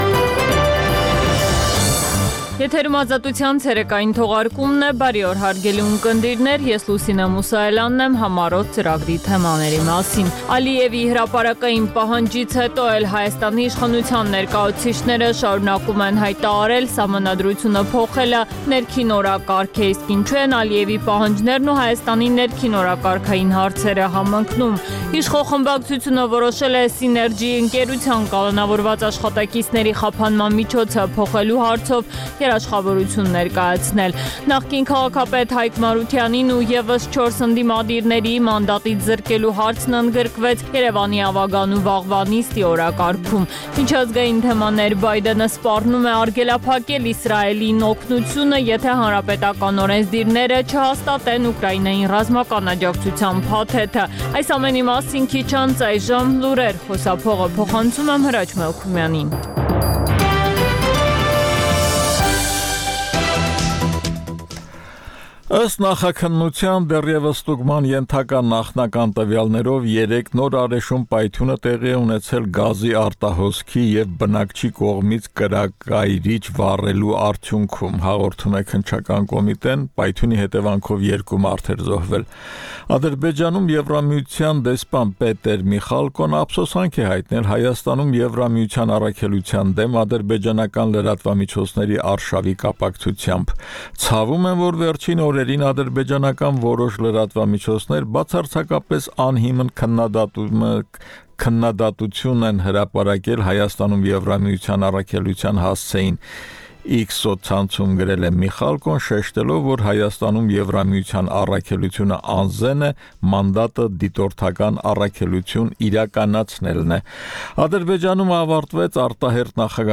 Տեղական եւ միջազգային լուրեր, ռեպորտաժներ զարգացող իրադարձությունների մասին, այդ թվում՝ ուղիղ եթերում, հարցազրույցներ, տեղական եւ միջազգային մամուլի տեսություն: